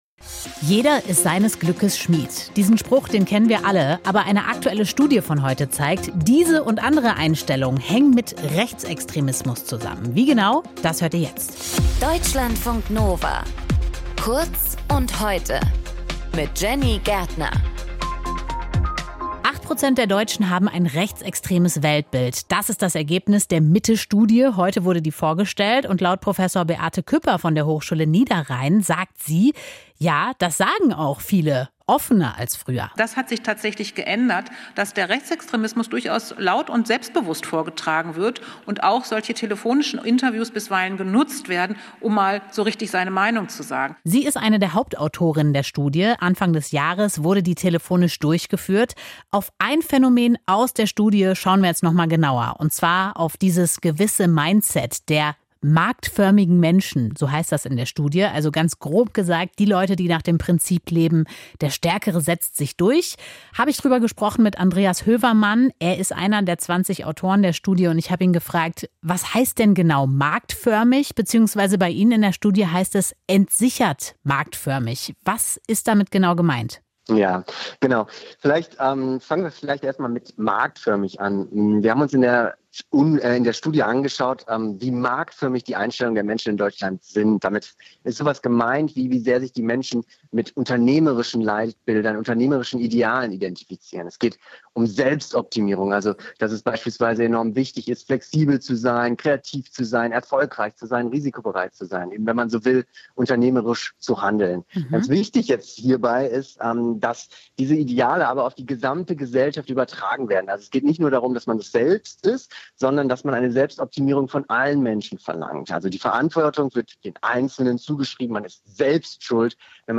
Kommentar zum politischen Dialog